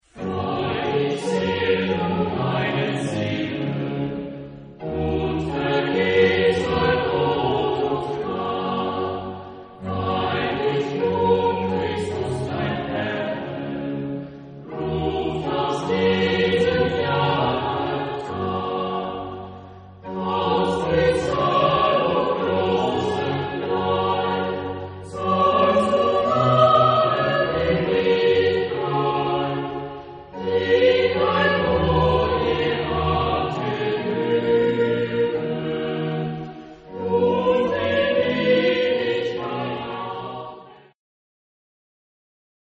Genre-Style-Forme : Sacré
Type de choeur : SATB  (4 voix mixtes )